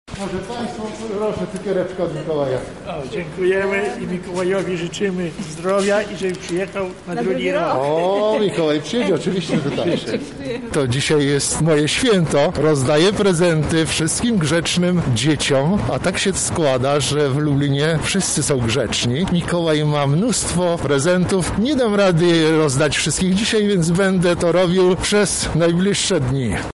Święty Mikołaj